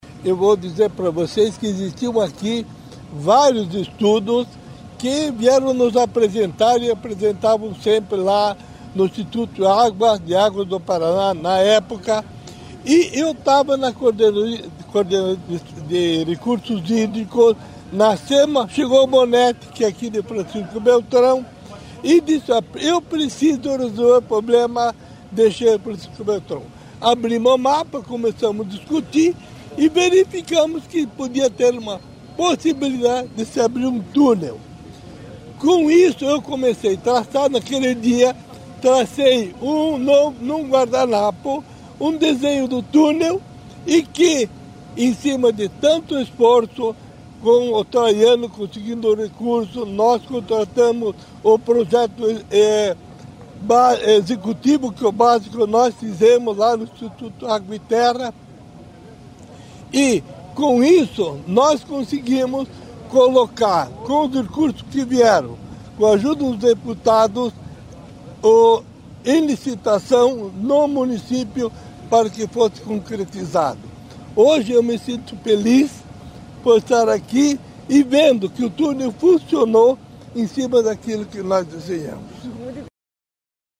Sonora do diretor-presidente do IAT, José Luiz Scroccaro, sobre os investimentos em contenção de cheias em Francisco Beltrão